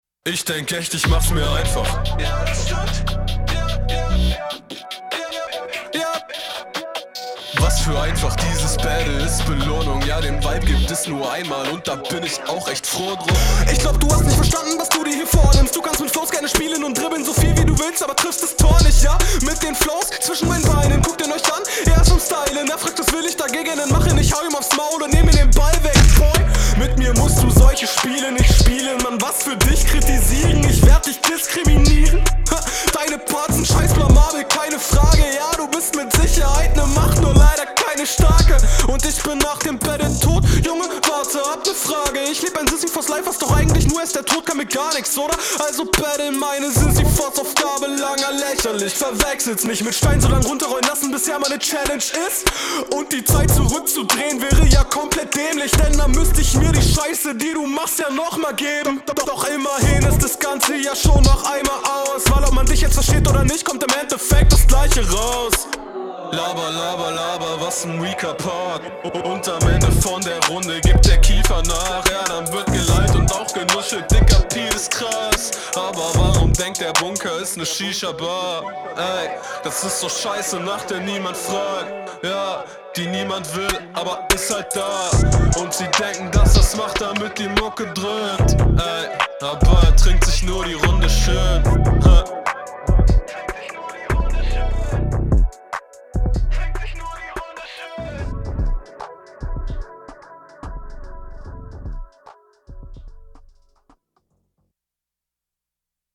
Ok flowtechnisch schon mal deutlich knackiger.